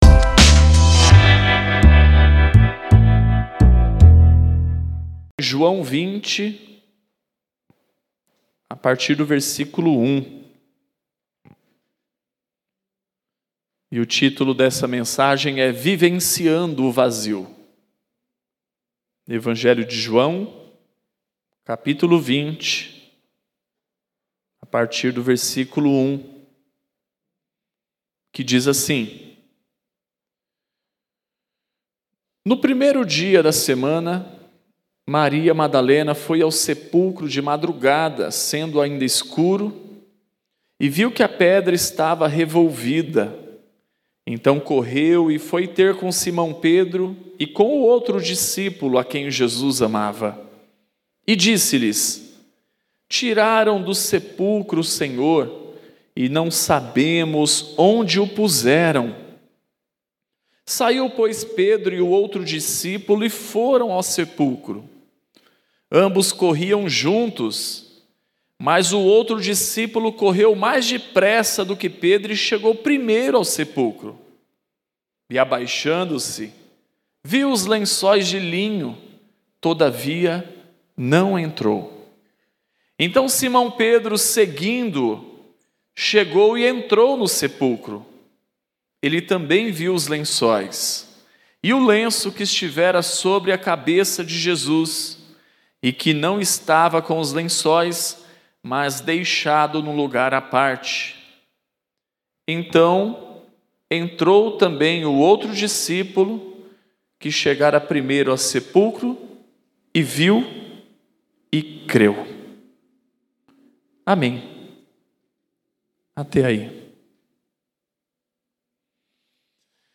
Mensagem especial de Páscoa, realizado no Sábado.